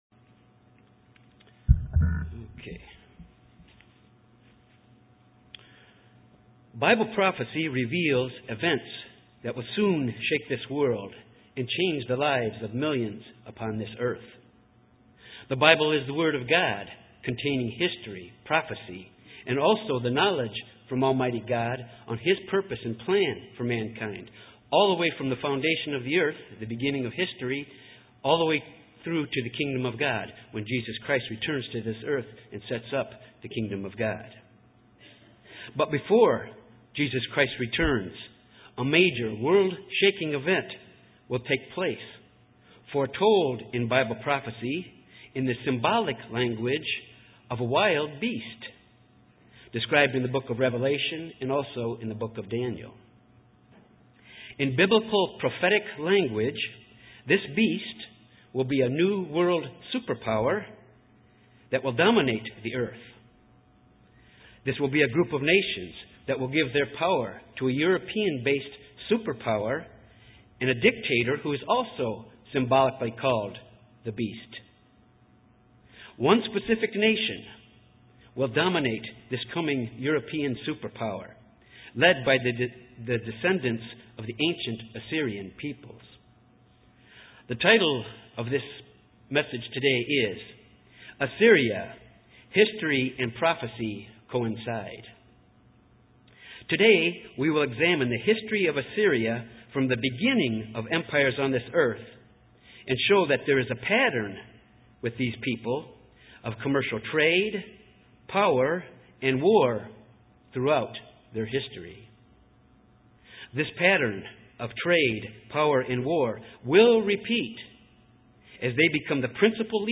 Given in Little Rock, AR
By examining the history of Assyria, you will see this pattern of economic growth, trading power followed by militarism have been repeated throughout history UCG Sermon Studying the bible?